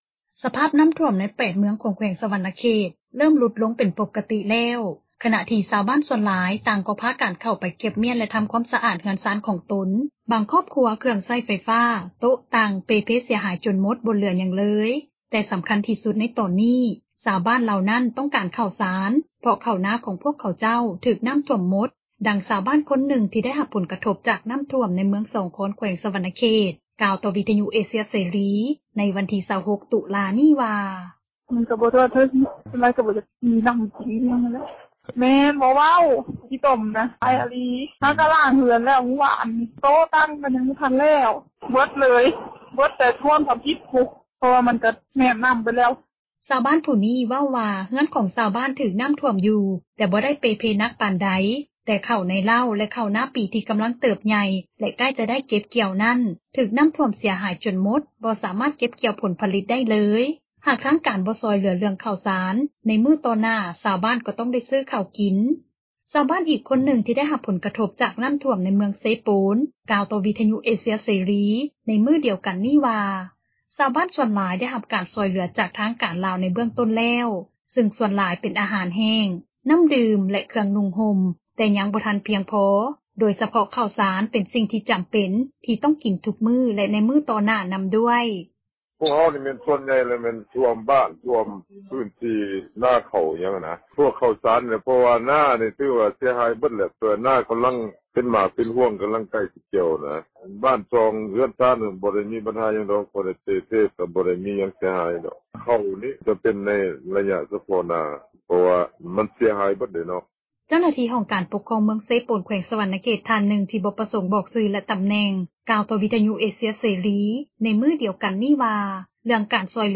ສະພາບນໍ້າຖ້ວມ ໃນ 8 ເມືອງຂອງແຂວງສວັນນະເຂດ ເຣີ່ມຫຼຸດລົງ ເປັນປົກກະຕິແລ້ວ ຂນະທີ່ຊາວບ້ານສ່ວນຫຼາຍ ຕ່າງກໍພາກັນເຂົ້າໄປ ເກັບມ້ຽນ ແລະທໍາຄວາມສະອາດ ເຮືອນຊານຂອງຕົນ, ບາງຄອບຄົວ ເຄື່ອງໃຊ້ໄຟຟ້າ, ໂຕະ, ຕັ່ງ ເປ່ເພເສັຍຫາຍຈົນໝົດ ບໍ່ເຫຼືອຫຍັງ ເລີຍ, ແຕ່ສໍາຄັນທີ່ສຸດໃນຕອນນີ້ ຊາວບ້ານເລົ່ານັ້ນ ຕ້ອງການເຂົ້າສານ ເພາະນາເຂົ້າ ຂອງພວກເຂົາເຈົ້າ ຖືກນໍ້າຖ້ວມໝົດ ບໍ່ມີຮອດເຂົ້າ ຈະກິນ, ດັ່ງຊາວບ້ານ ຄົນນຶ່ງ ທີ່ໄດ້ຮັບຜົລກະທົບ ຈາກນໍ້າຖ້ວມ ໃນເມືອງສອງຄອນ ແຂວງສວັນນະເຂດ ກ່າວຕໍ່ວິທຍຸເອເຊັຽເສຣີ ໃນມື້ ວັນທີ 26 ຕຸລາ ນີ້ວ່າ: